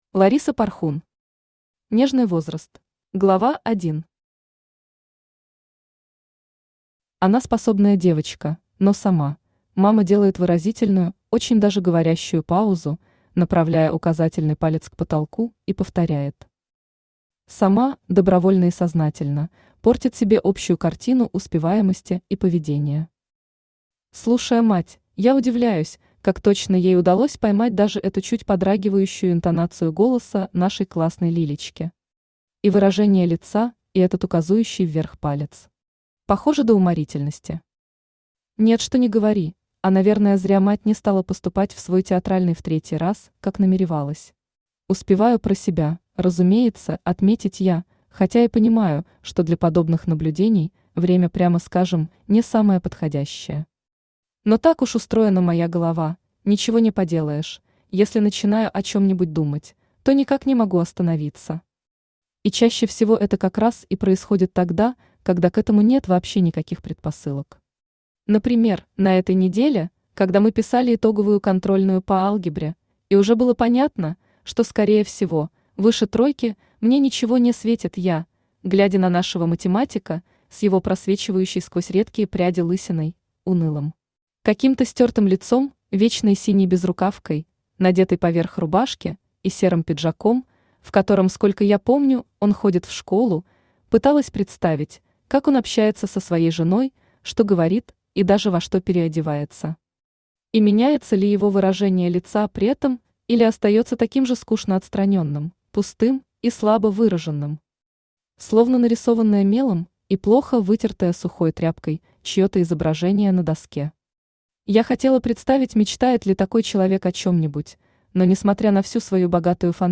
Аудиокнига Нежный возраст | Библиотека аудиокниг
Aудиокнига Нежный возраст Автор Лариса Порхун Читает аудиокнигу Авточтец ЛитРес.